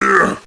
Heroes3_-_Pit_Lord_-_HurtSound.ogg